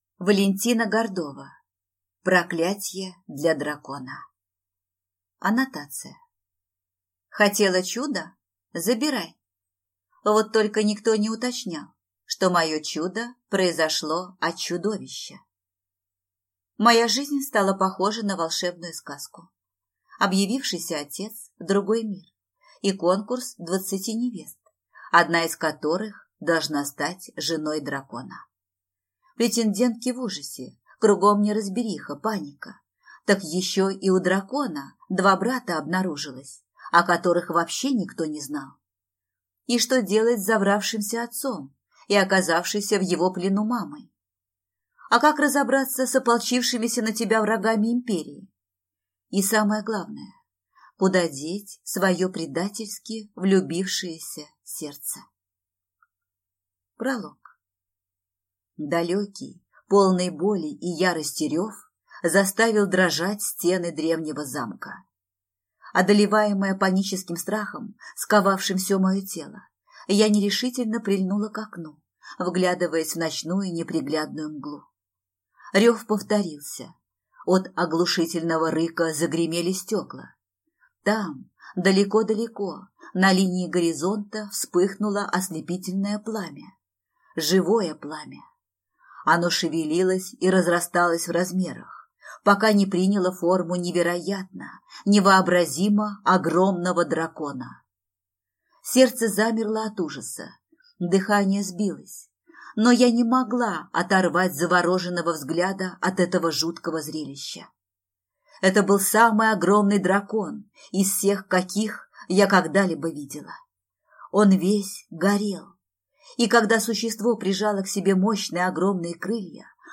Аудиокнига Проклятье для дракона | Библиотека аудиокниг